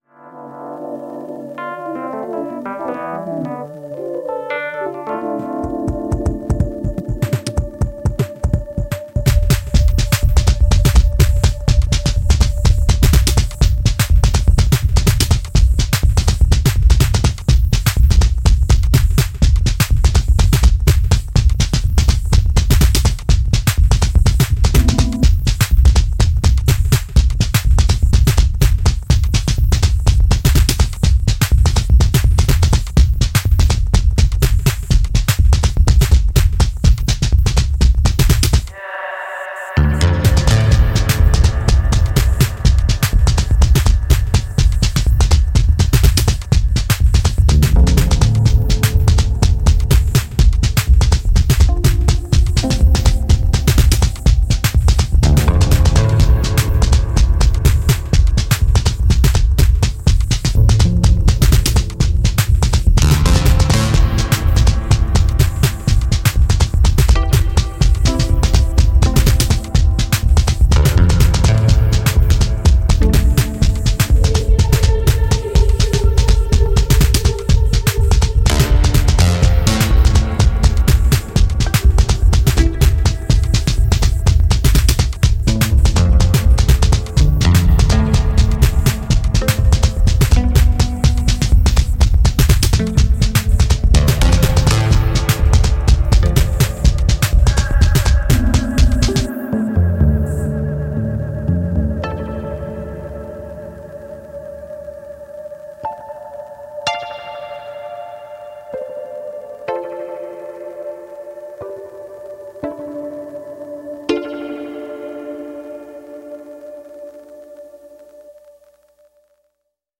2019 Genre: Electronic Style: Techno, Deep House,